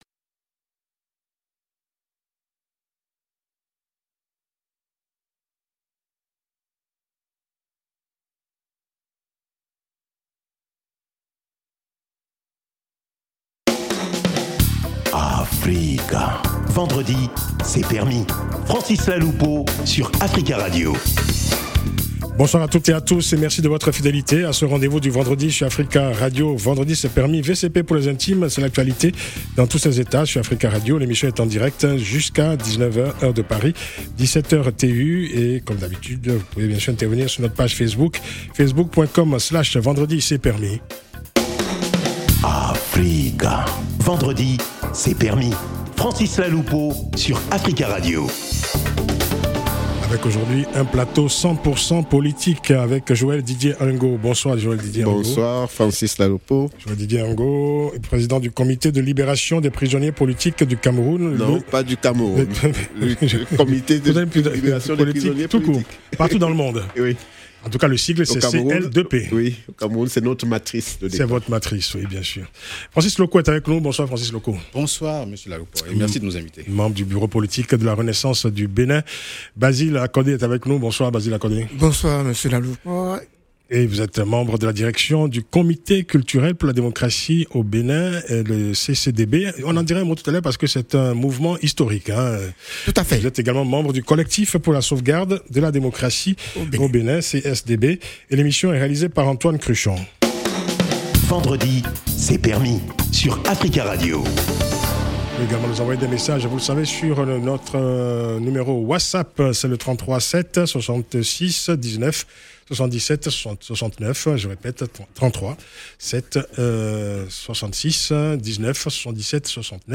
L’actualité de la semaine analysée et commentée par les débatteurs polémistes invités